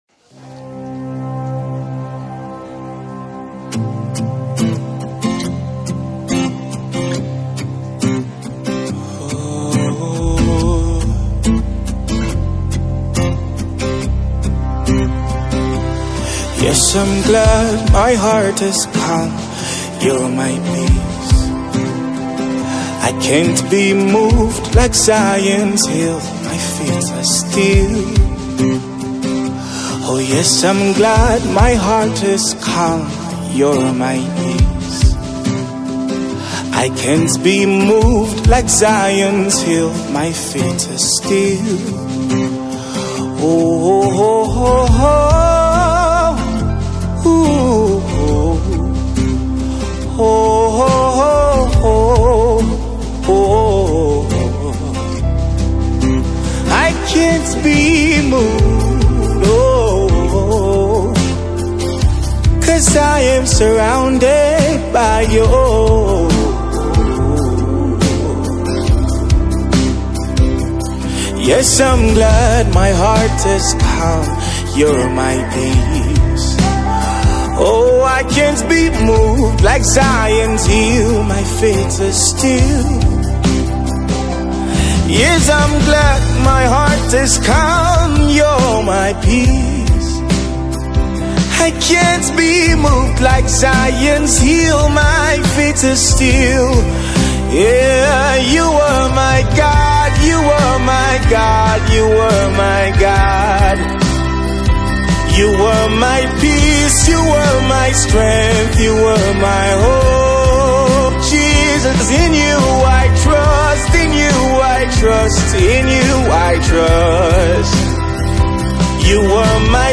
gospel music
soul lifting song